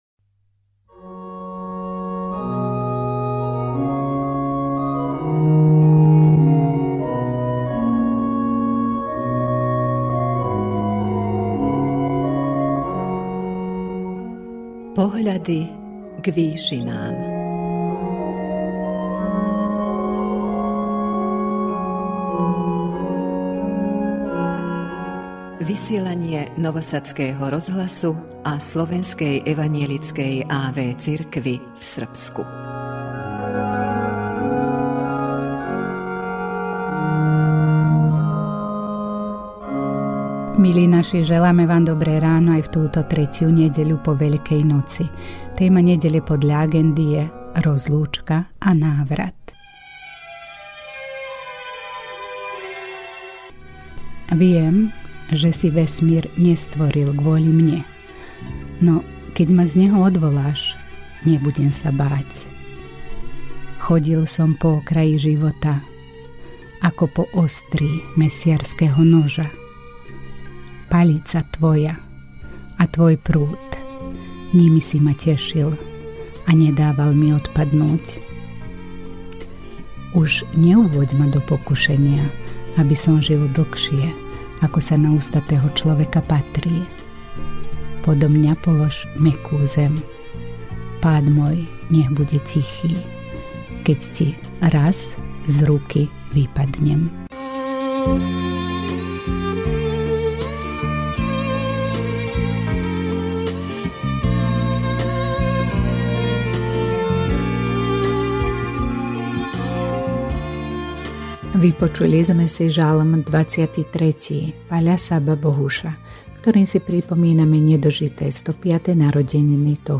V duchovnej relácii Pohľady k výšinám Rádia Nový Sad a Slovenskej evanjelickej a.v. cirkvi v Srbsku v túto 3. nedeľu po Veľkej noci odznela duchovná úvaha